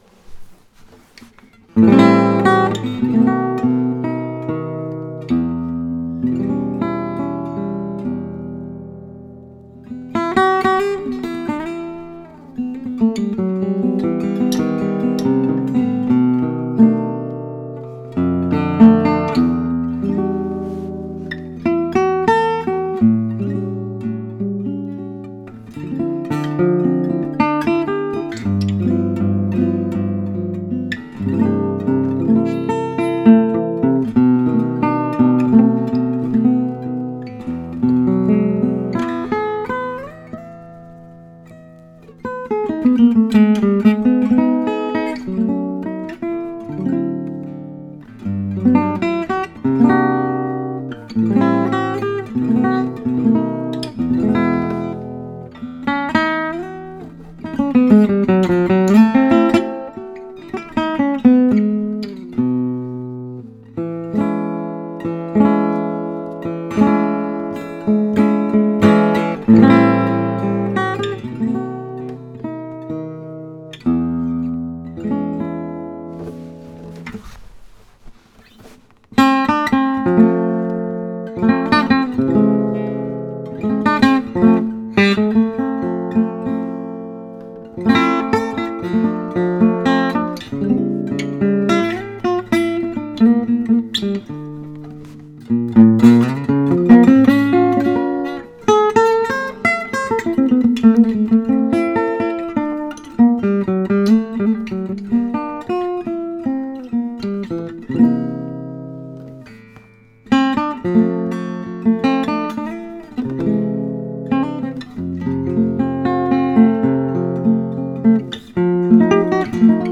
I noodled a bit on the guitar just now -
Nice playing and the Di Mauro sounds beautiful and mature, and what a growl!
What a beautiful and big sound!
Sounds delightful!